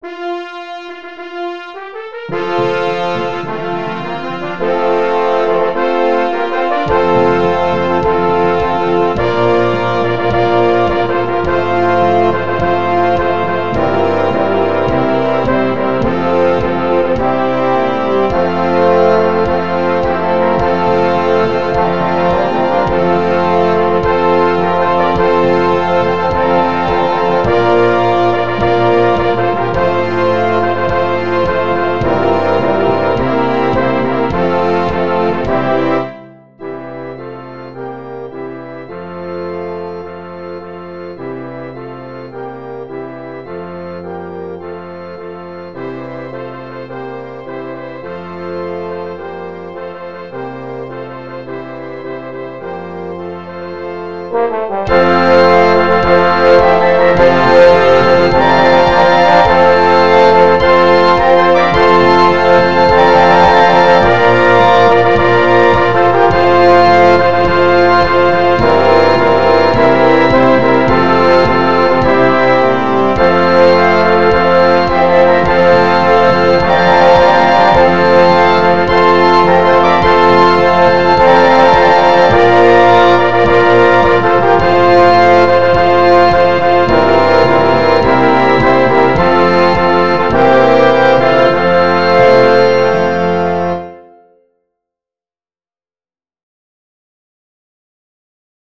Blasmusik & Orchester